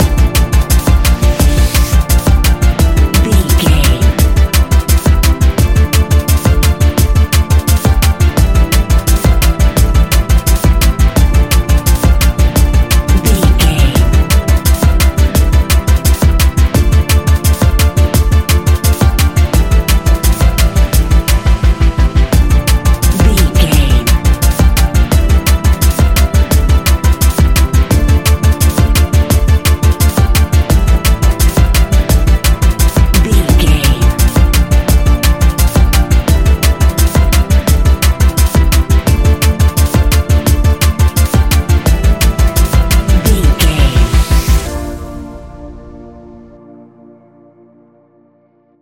Ionian/Major
A♯
electronic
techno
trance
synthesizer
synthwave